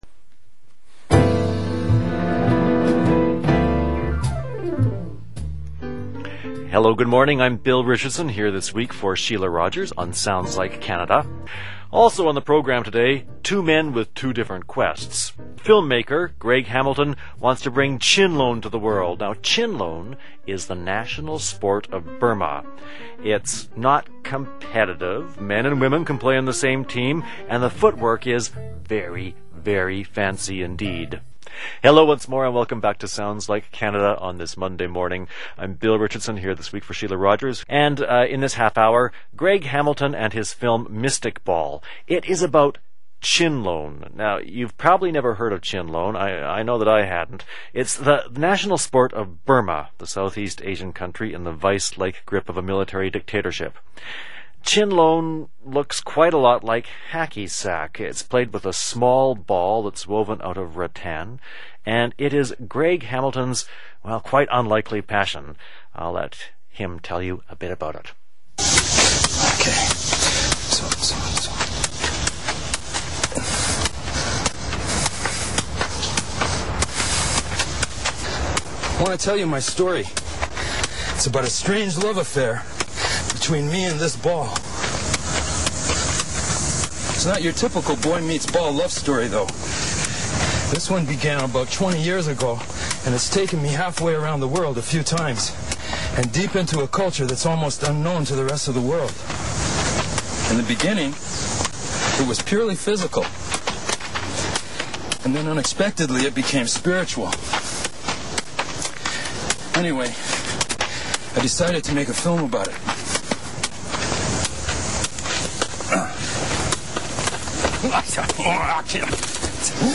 Listen to Interviews and Reviews � CBC Radio Canada, "Sounds Like Canada"